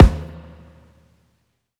Springlive_kick.wav